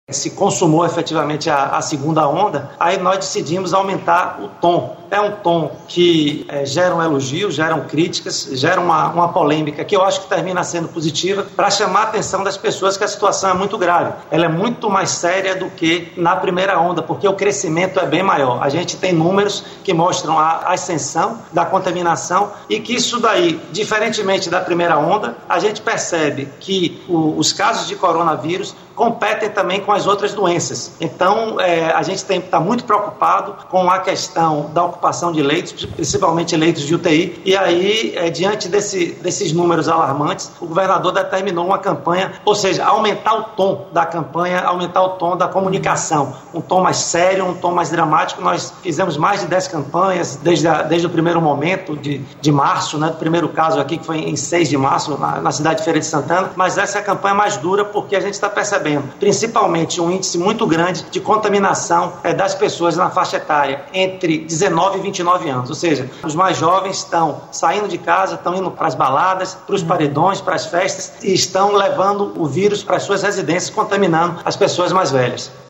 Entrevista-FN.mp3